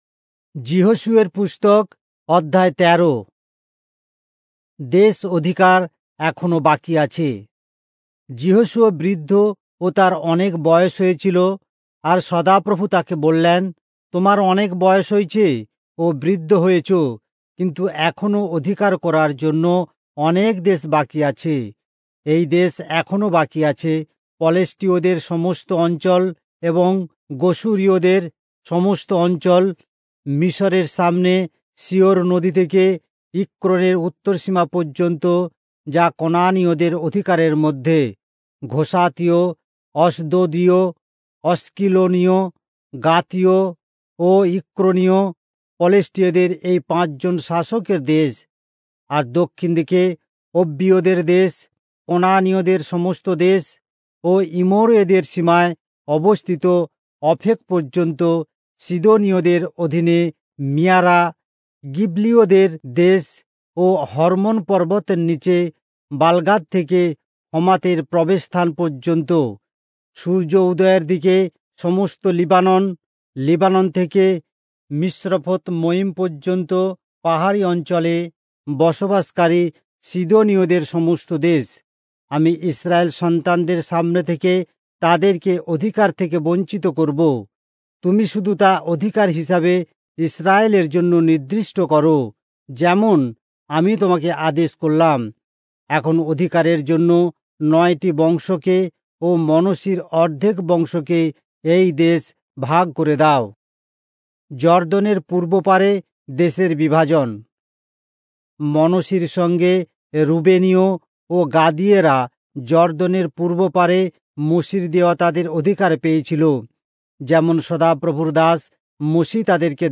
Bengali Audio Bible - Joshua 24 in Irvbn bible version